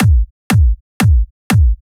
120 BPM Beat Loops Download